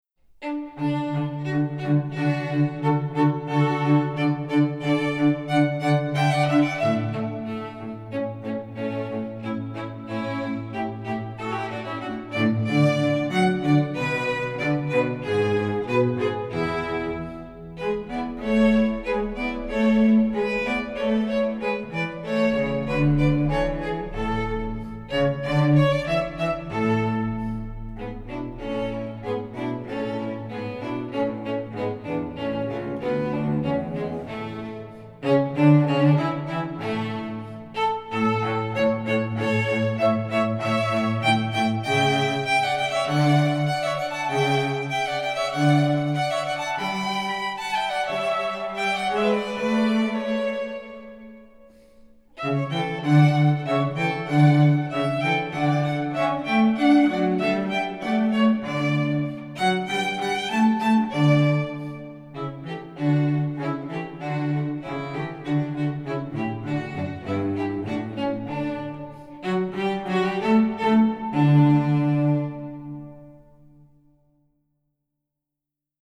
Voicing: String Trio